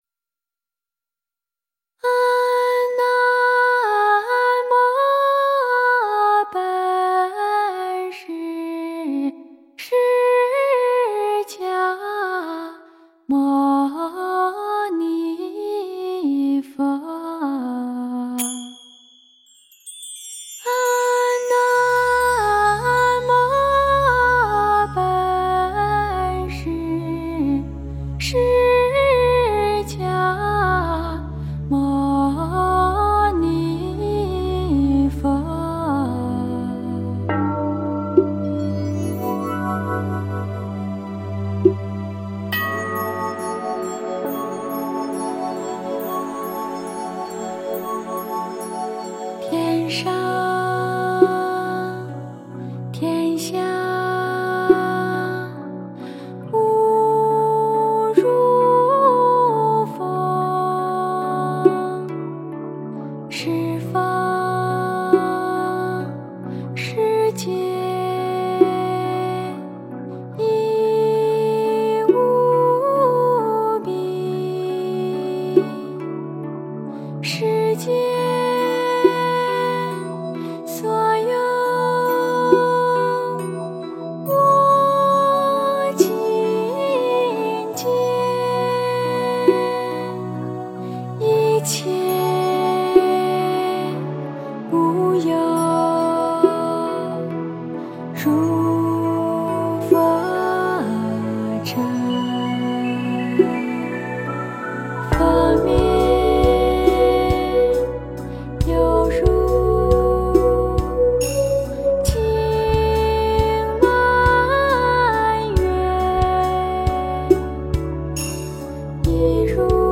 佛音 诵经 佛教音乐 返回列表 上一篇： 劝君 下一篇： 心经 相关文章 百字明咒--未知 百字明咒--未知...